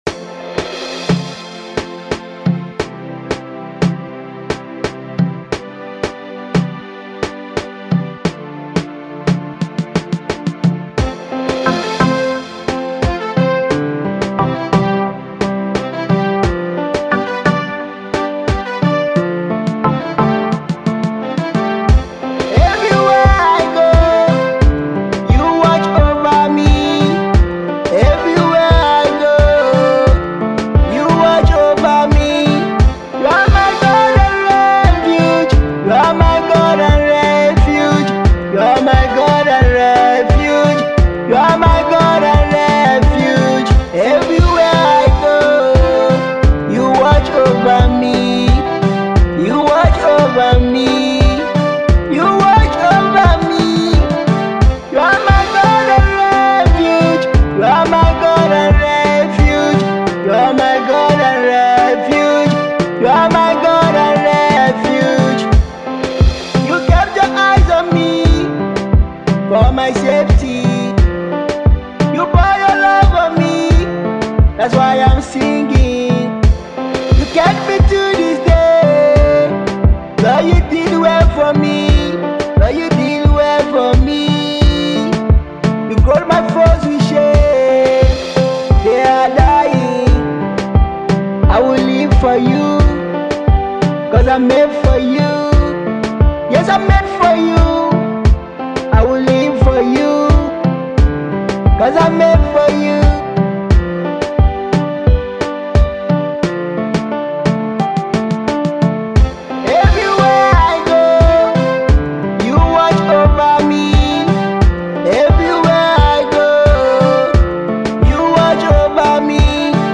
afro singer
beautiful heart touching melody song